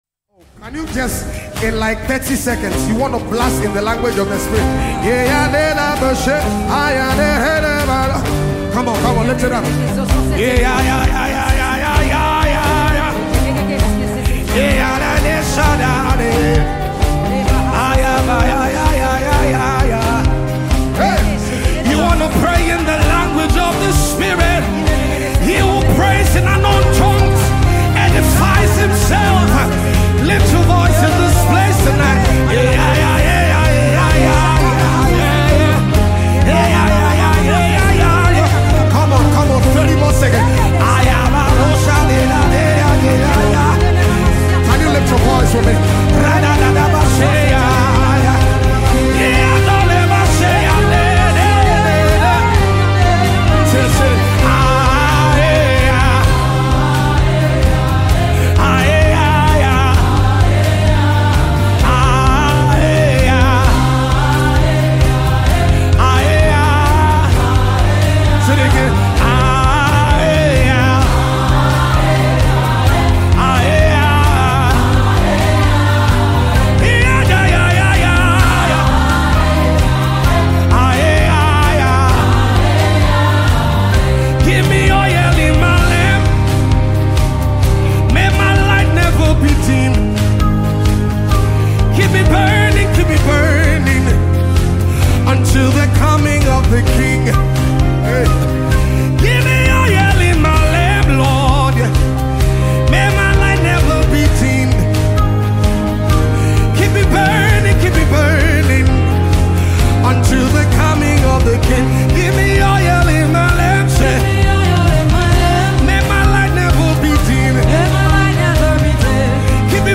" a beautiful and soul-stirring gospel track